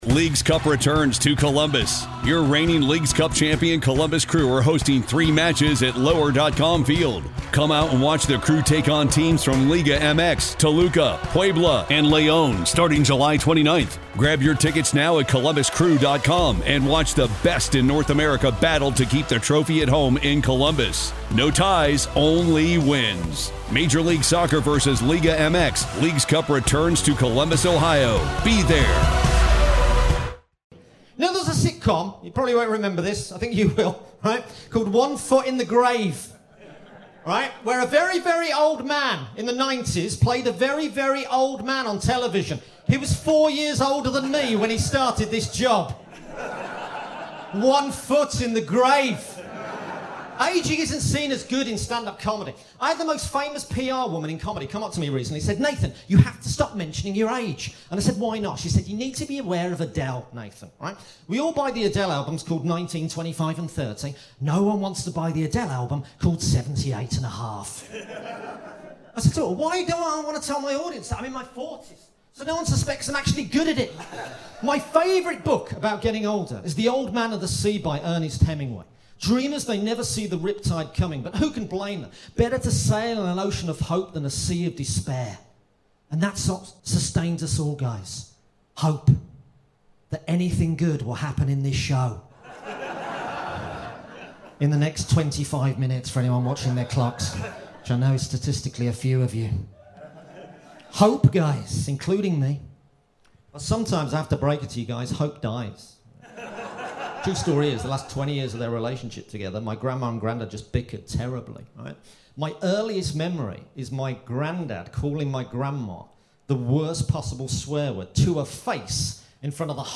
Recorded Live at Just The Tonic Edinburgh 2023.